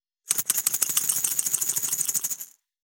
349岩塩を振る,調味料,カシャカシャ,ピンク岩塩,
効果音厨房/台所/レストラン/kitchen